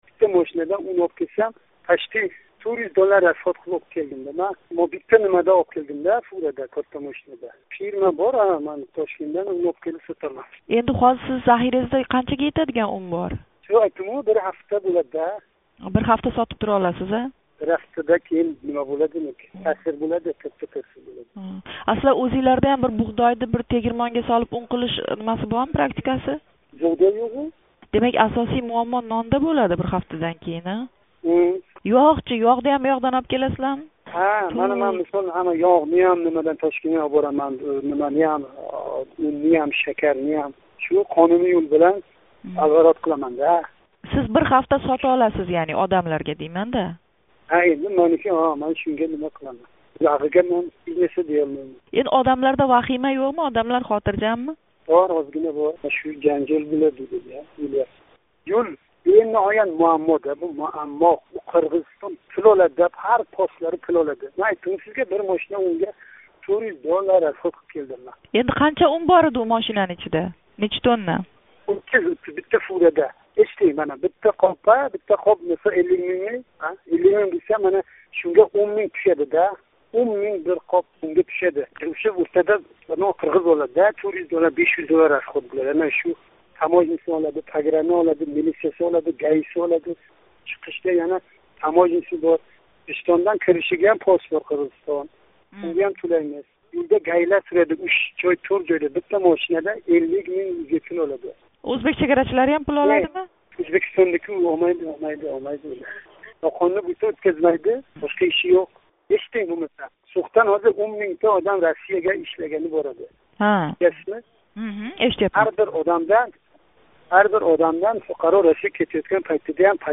Сўхлик тадбиркор билан суҳбат.